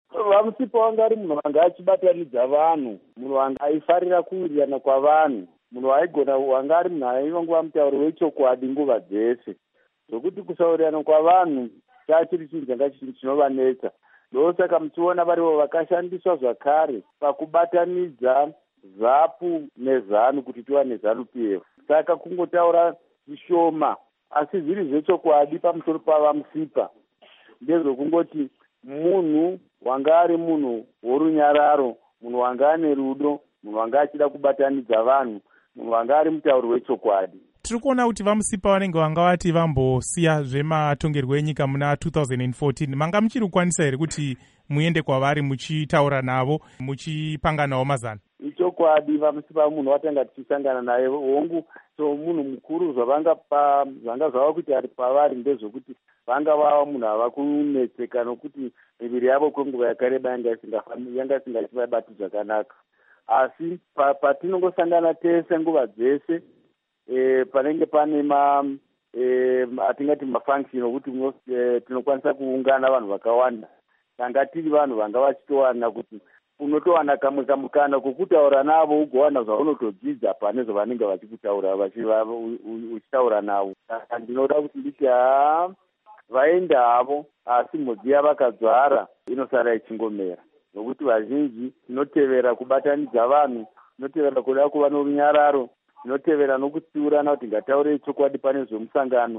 Hurukuro naVaJoram Gumbo